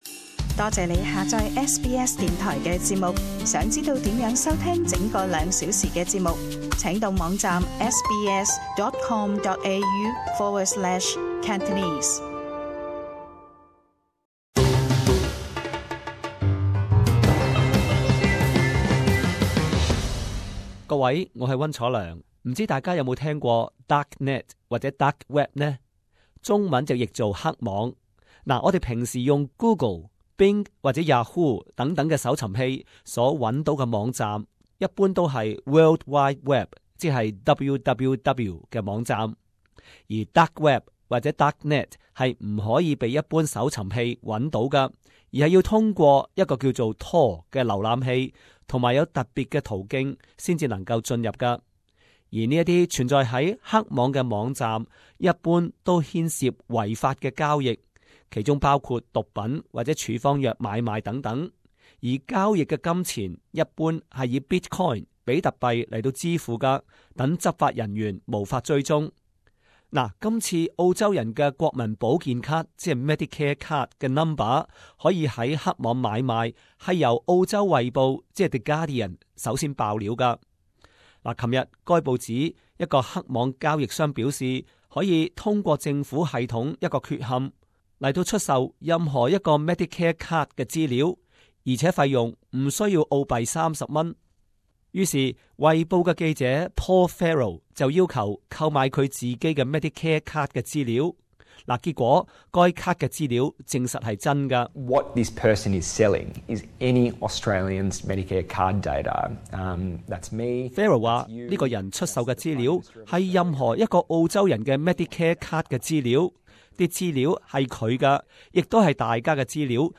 【時事報導】 黑網出售國民保健卡號碼 聯邦警方出動調查